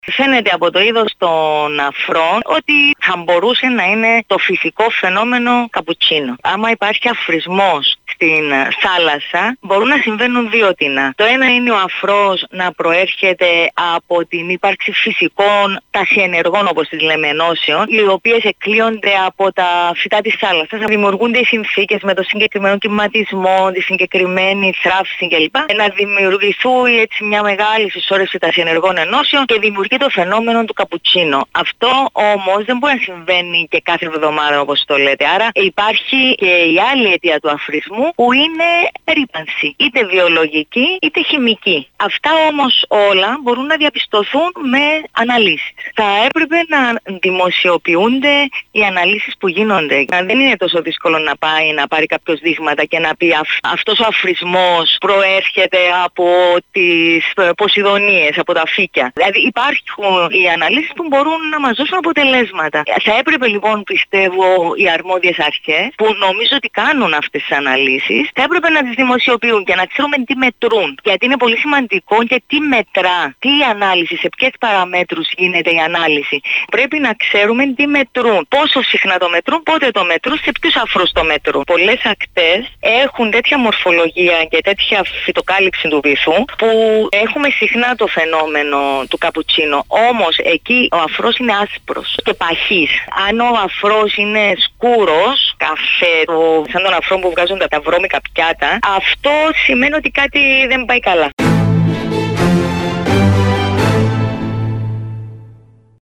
τόνισε μιλώντας στην πρωϊνή ενημερωτική εκπομπή στο Κανάλι 6